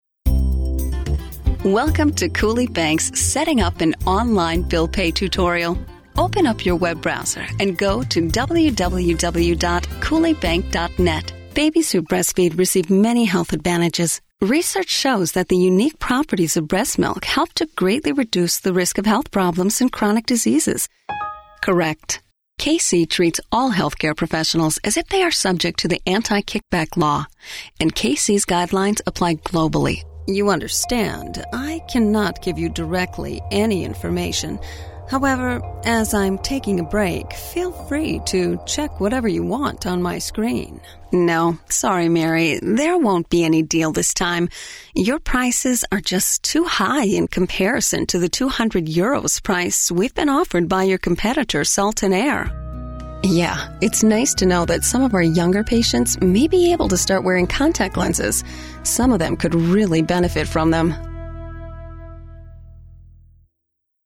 middle west
Sprechprobe: eLearning (Muttersprache):